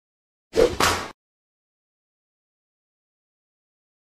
High Five (Jacksepticeye) Sound